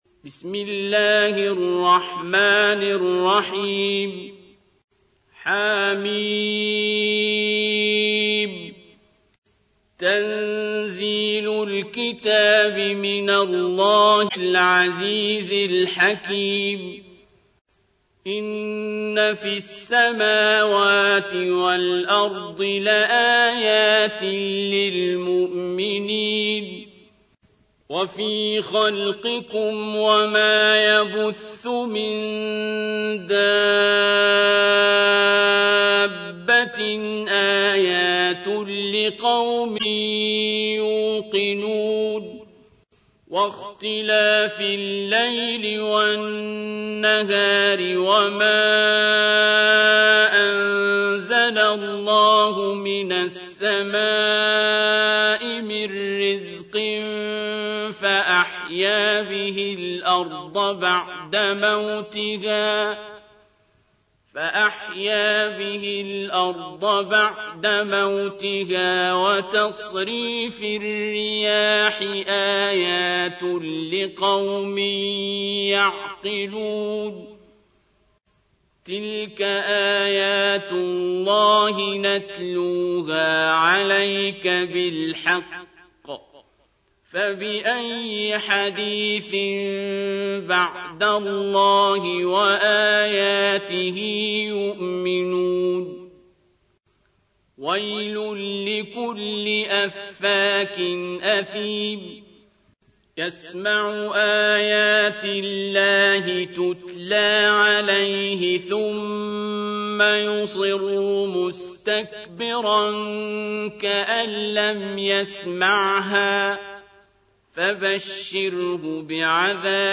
تحميل : 45. سورة الجاثية / القارئ عبد الباسط عبد الصمد / القرآن الكريم / موقع يا حسين